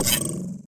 Select Robot 1.wav